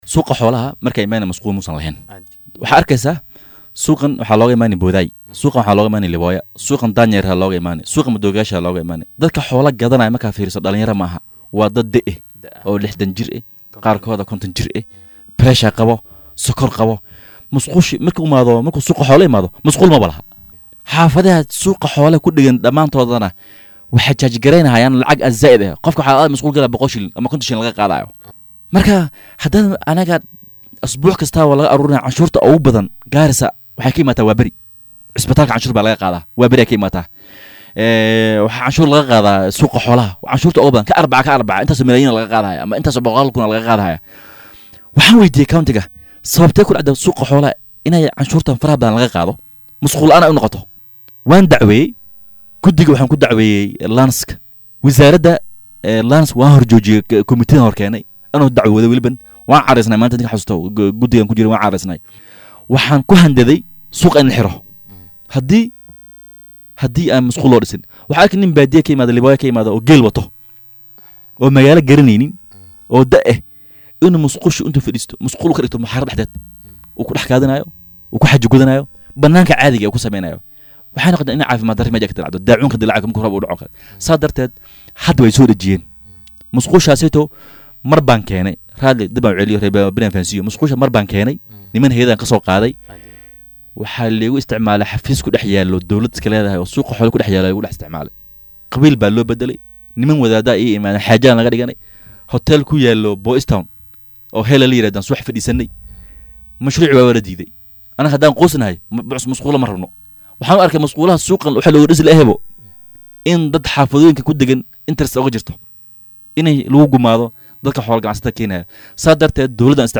Wakiilka laga soo doortay waaxda Waaberi ee Magaalada Garissa Abubakar Xaaji Sugow oo saaka marti inoogu ahaa Barnaamijka Hoggaanka Star ayaa ka warbixiyay waxyaabo badan oo ku saabsan Hormarka Waaberi.